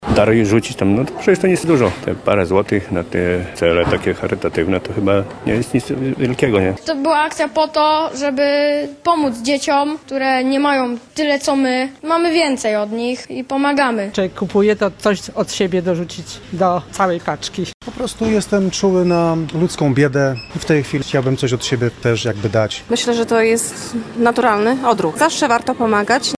Setki kolorowych pudeł, szeleszczących reklamówek i ciepłych gestów- tak wyglądała niedziela (07.12) na ulicach Lublina podczas Wielkiej Ulicznej Zbiórki Darów w ramach akcji Pomóż Dzieciom Przetrwać Zimę.
Zapytaliśmy lublinian, co sprawiło, że w tym przedświątecznym zabieganiu znaleźli czas, by podzielić się dobrem:
SONDA